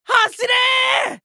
青年ボイス～ホラー系ボイス～